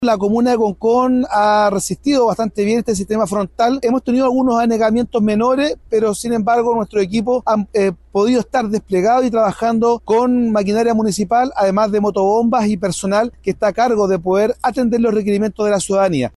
En otras comunas como Concón, pese a diferentes anegamientos que se registraron por las lluvias, el alcalde Freddy Ramírez, señaló que han resistido el sistema frontal de buena forma.
cu-sistema-frontal-freddy-ramirez.mp3